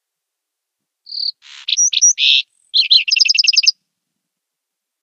Звуки птиц. Sounds of birds.
Звук пения птицы.